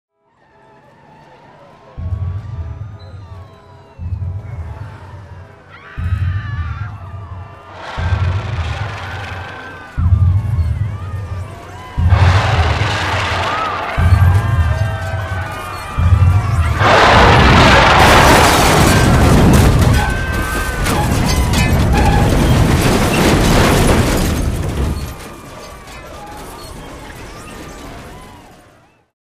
Звуки ударов, разрушения
Звук монстра, шагающего по городу и крушащего здания (Годзилла или Кинг Конг)